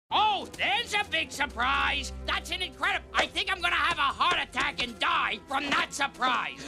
Play, download and share IagoSurprise original sound button!!!!
iago-jafars-parrot-is-sarcastically-surprised.mp3